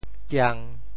僵（殭） 部首拼音 部首 亻 总笔划 15 部外笔划 13 普通话 jiāng 潮州发音 潮州 giang1 文 中文解释 僵 <动> (形声。